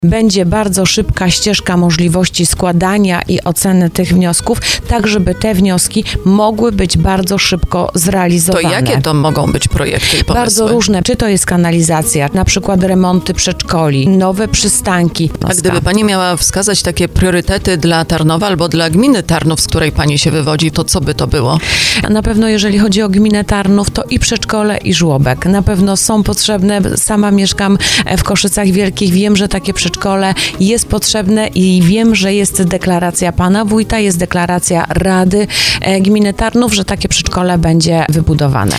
Jak mówiła w porannym programie Słowo za Słowo poseł PiS Anna Pieczarka, po latach planowania przyszedł czas na konkretne działanie, na które Tarnów i okoliczne miejscowości czekały od lat.